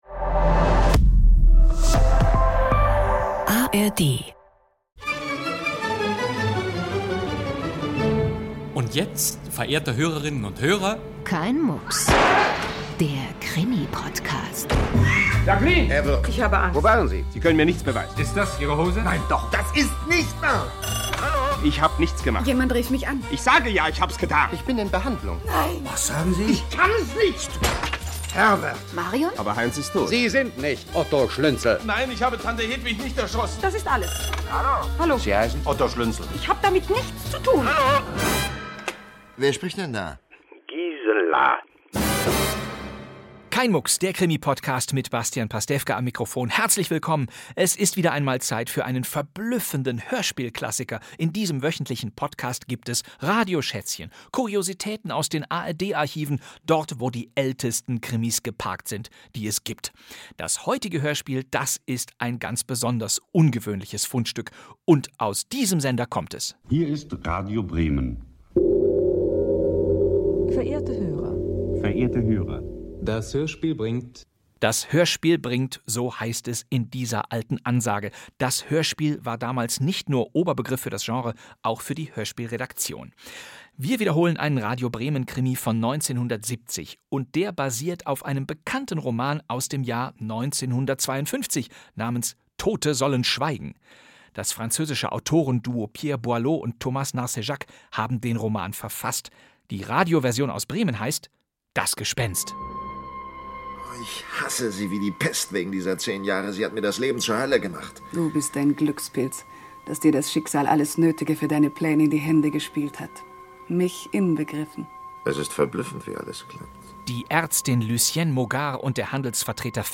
Krimi-Podcast mit Bastian Pastewka 1:03:27 Play Pause 5M ago 1:03:27 Play Pause Αναπαραγωγή αργότερα Αναπαραγωγή αργότερα Λίστες Like Liked 1:03:27 Ein Spionage-Thriller in drei Folgen: Der ungarische Lehrer Joseph Vadassy will an der französischen Rivera Urlaub machen. Als er seine Fotos vor Ort entwickeln lassen möchte, wird er festgenommen.